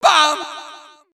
baBumBumBum_Farthest4.wav